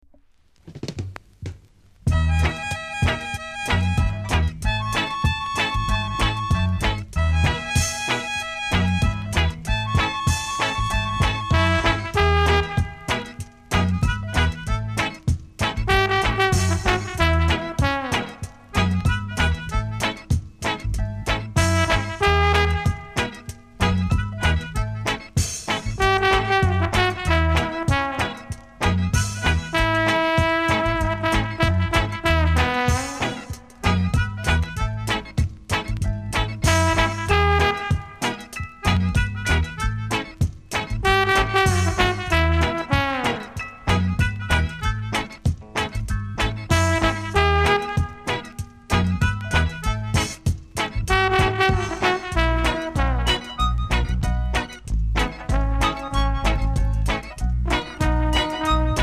※小さなチリノイズが少しあります。
INST CUT!!